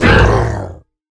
Minecraft Version Minecraft Version snapshot Latest Release | Latest Snapshot snapshot / assets / minecraft / sounds / mob / ravager / hurt2.ogg Compare With Compare With Latest Release | Latest Snapshot
hurt2.ogg